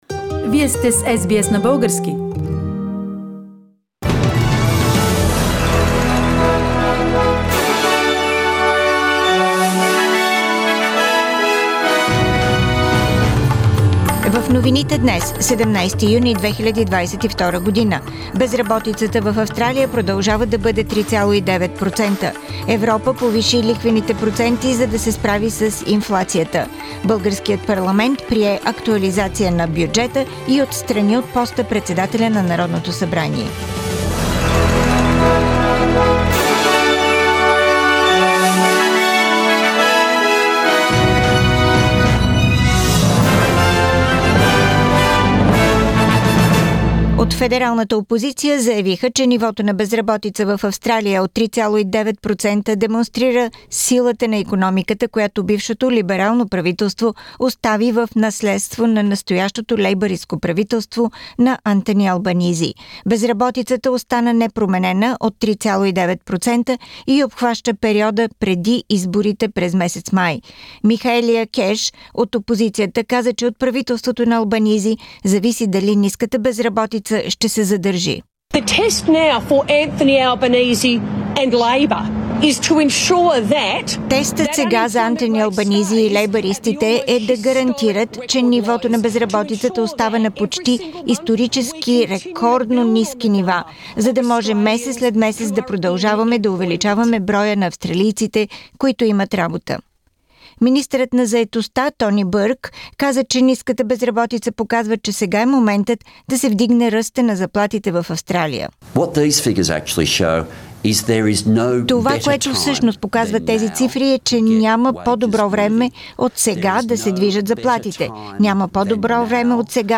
Weekly Bulgarian News – 17th Jun 2022